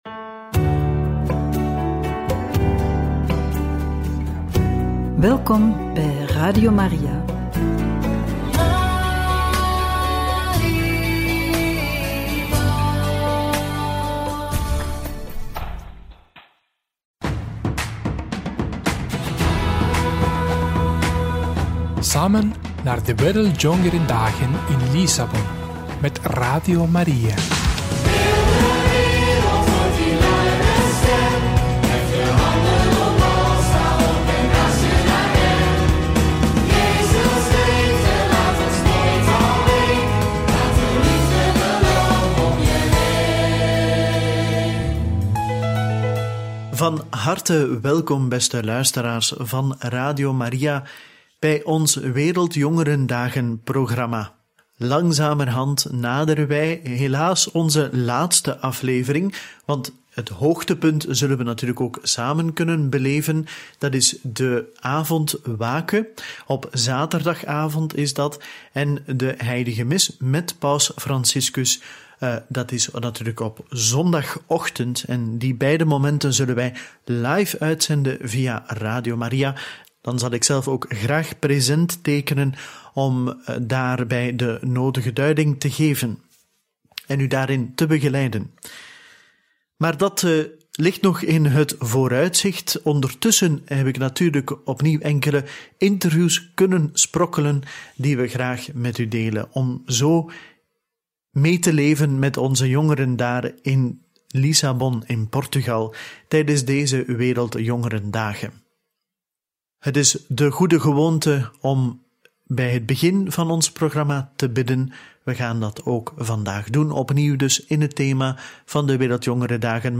live tijdens de verwelkoming van de Paus!